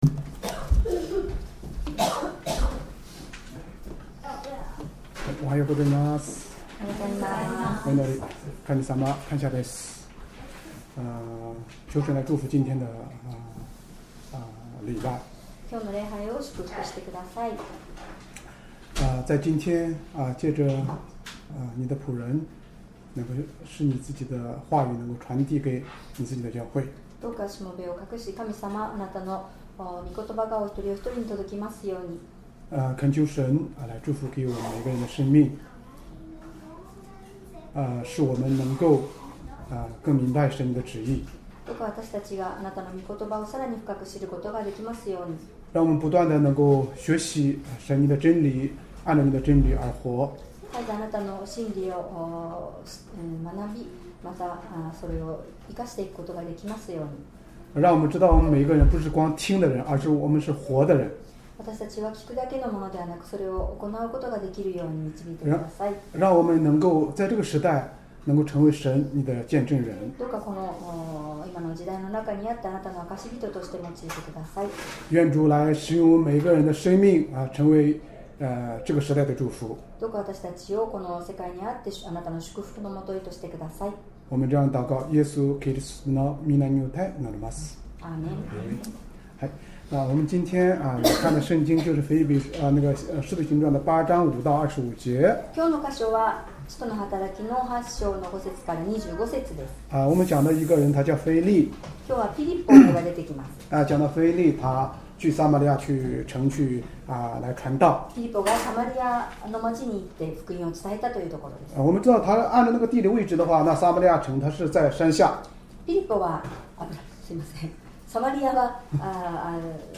Sermon
2025年4月13日 主日礼拝 説教 「ピリポのサマリア伝道」 聖書 使徒の働き 8章 5～25節 8:5 ピリポはサマリアの町に下って行き、人々にキリストを宣べ伝えた。